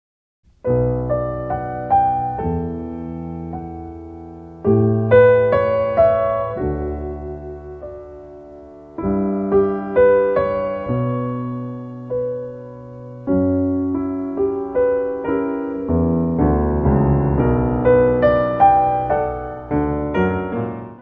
piano
guitarra.
Área:  Música Clássica